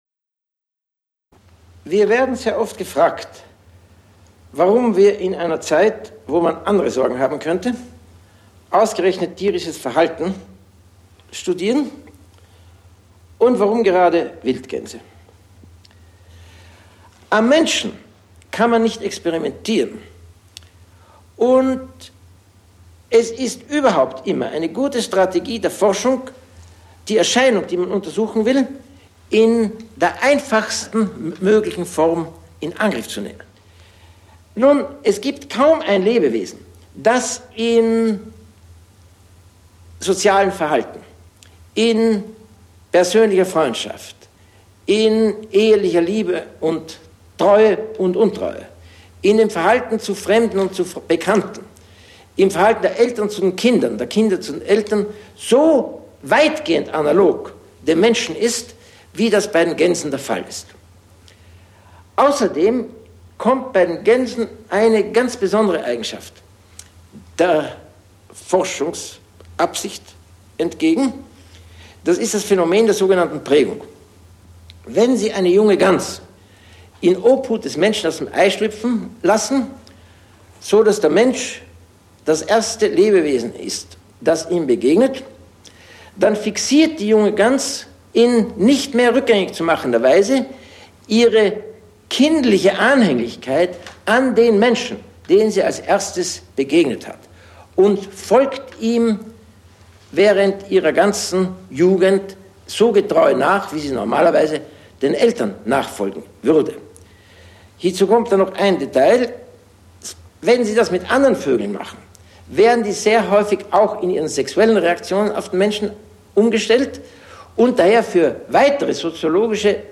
Originaltonaufnahmen 1951-1983
Im Gespräch mit Erich von Holst 34:22